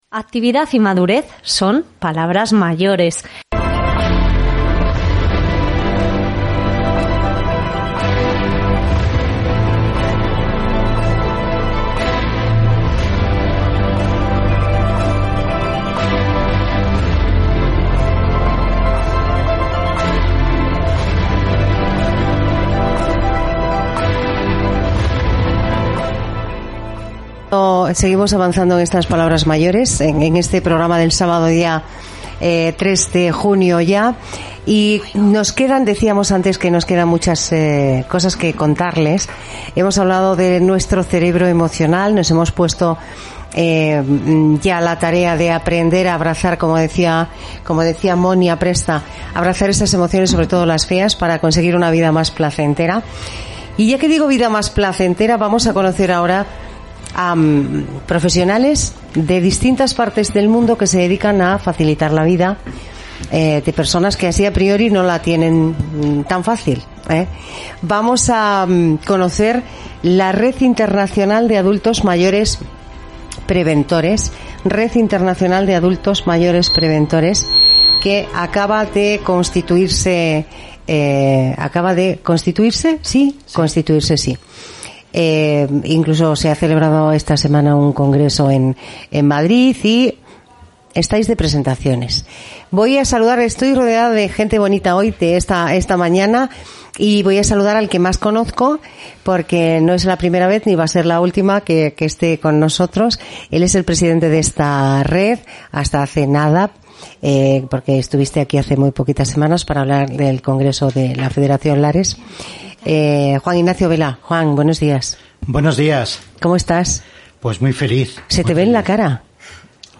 Hemos podido conversar con lgunos de los representantes de algunas de las entidades que hoy sí forman parte de la Red, y queda claro que los mayores son cada vez más necesarios como instrumentos transformadores de la sociedad.